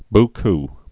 (bk)